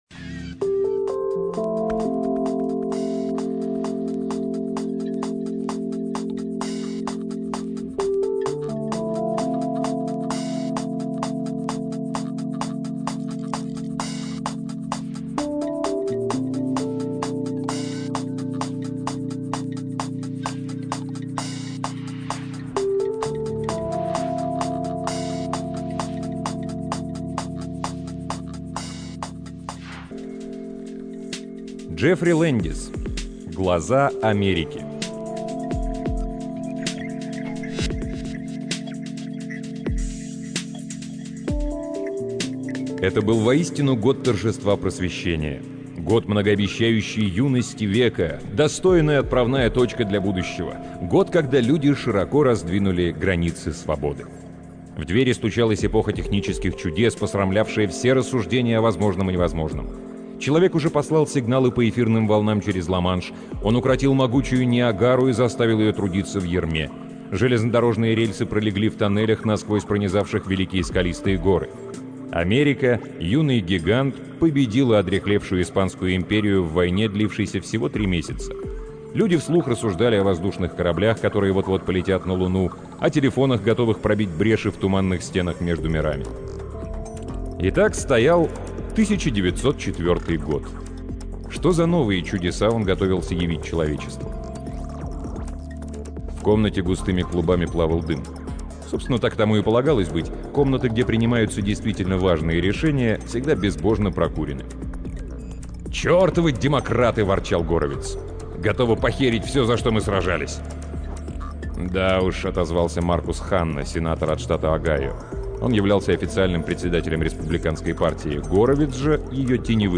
Аудиокнига Джеффри Лэндис — Глаза Америки